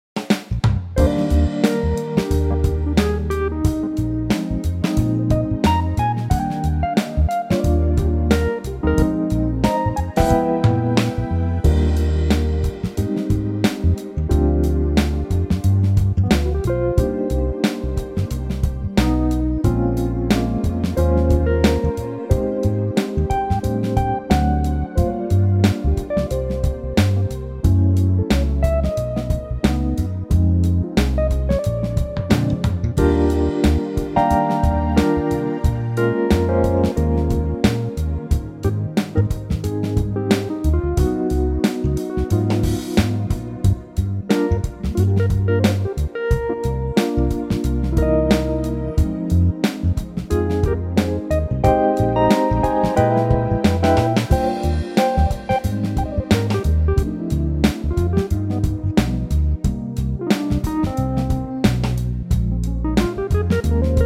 Unique Backing Tracks
key - Eb - vocal range - Eb to F